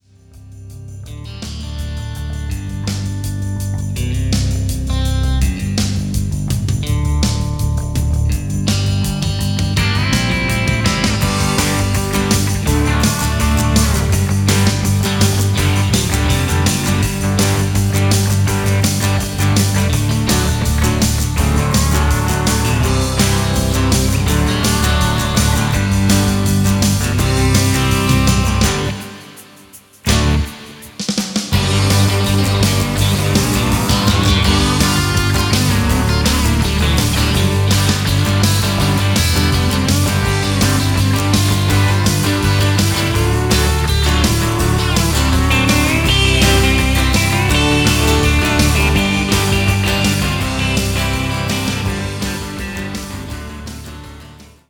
Ein "Must Have" für alle Country-Rock Fans!